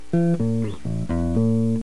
(bass3)
si_se_acabo_bass3.mp3